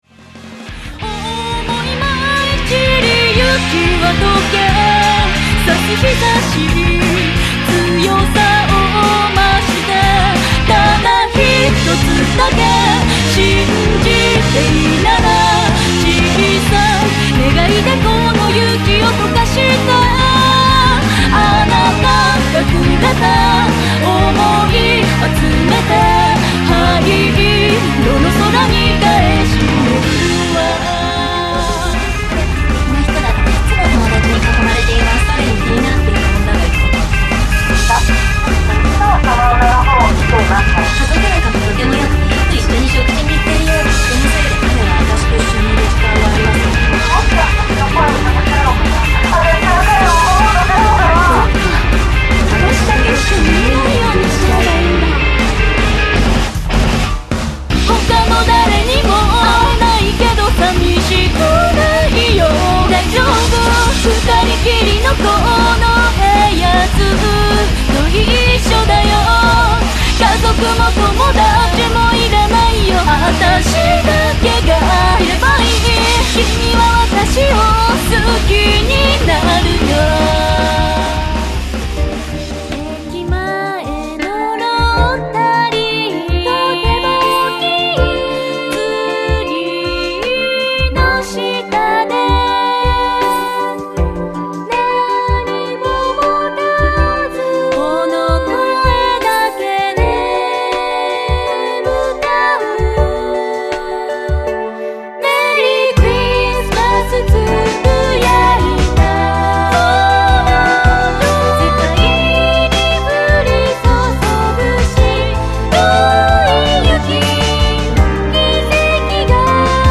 (Piano arrange)
全曲クロスフェードデモ（4'13"/3.37MB）